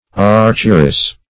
Archeress \Arch"er*ess\, n. A female archer.